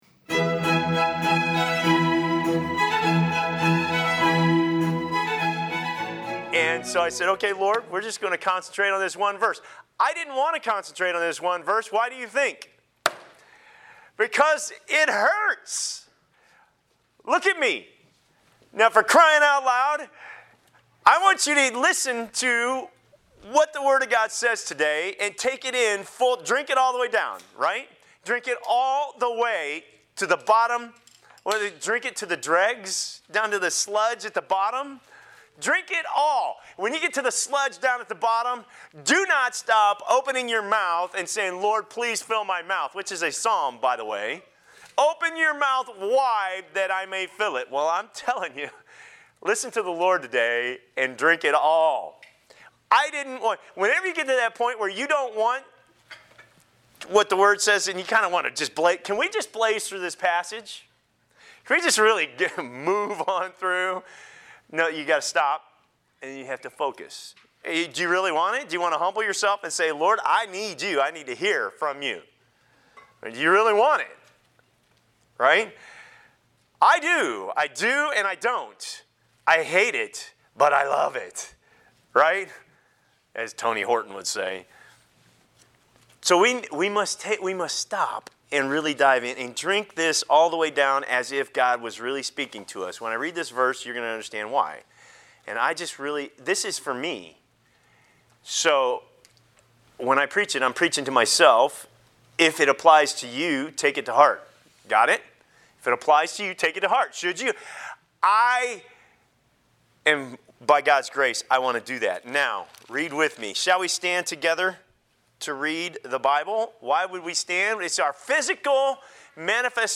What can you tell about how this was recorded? October 6, 2019 Resisting Sin to the Point of Death Passage: Hebrews 12.4 Service Type: Morning Worship Service Bible Text: Hebrews 12.4 | Everyone likes to be surrounded with affirmation.